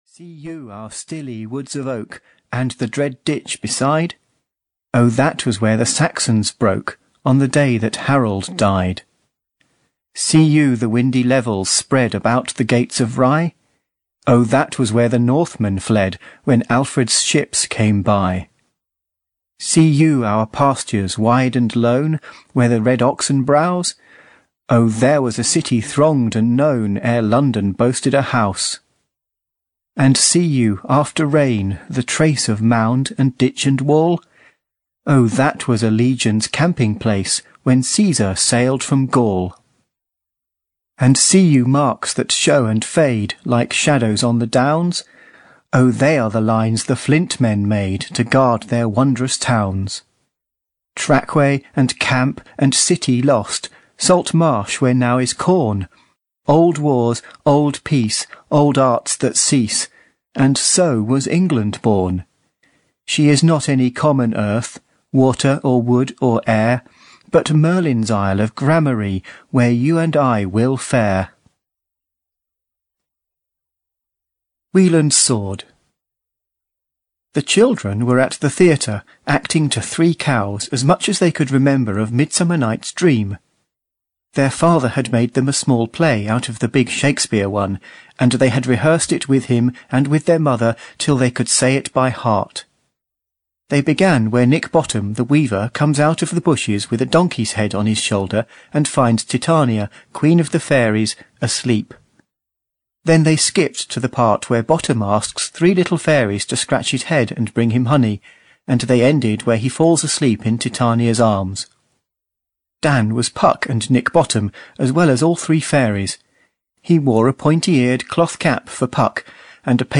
Puck of Pook's Hill (EN) audiokniha
Ukázka z knihy